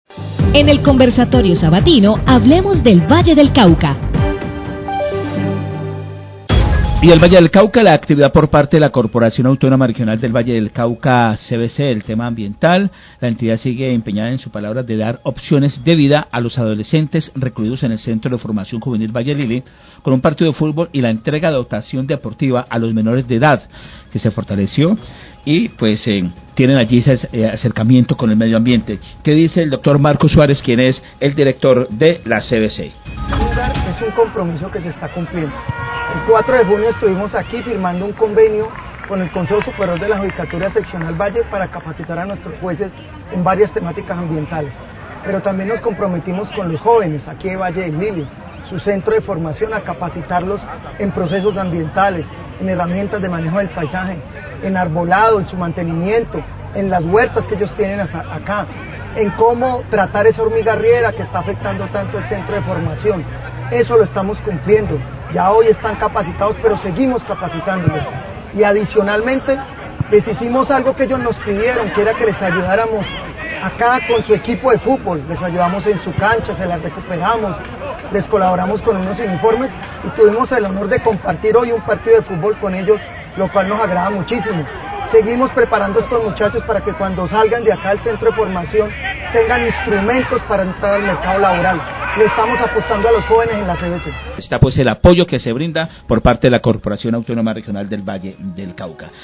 El director general de la CVC. Marco Antonio Suárez, habla del convenio con el Consejo Seccional de la Judicatura para realizar capacitaciones ambientales sobre en jardinería, arboricultura, agricultura orgánica y otras temáticas, con las cuales se busca la reinserción de jóvenes infractores del centro de formacion Valle del Lilly.